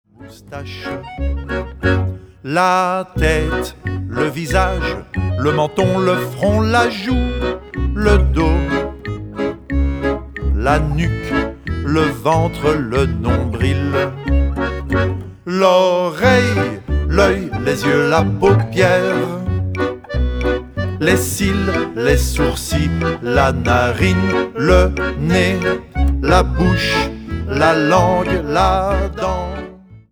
Genre : World